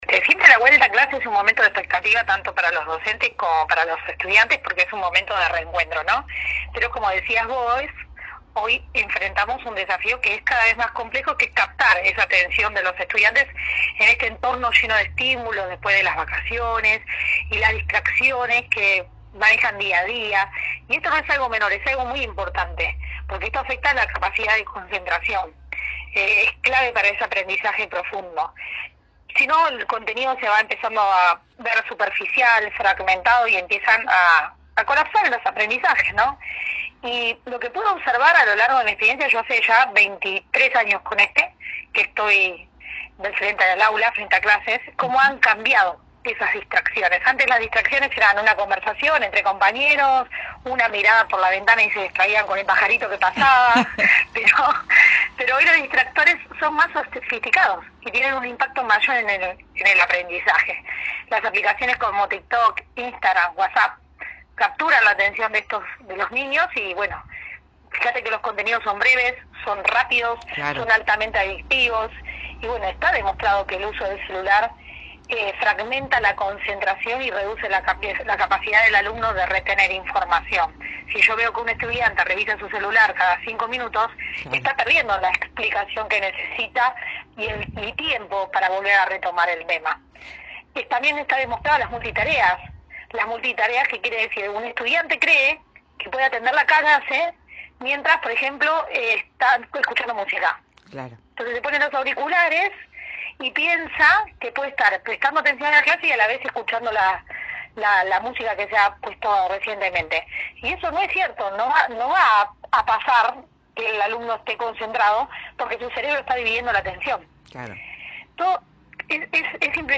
La ex atleta olímpica y actual secretaria de Deportes de Río Tercero pasó por el estudio móvil de Cadena 3 y habló sobre su historia y actualidad.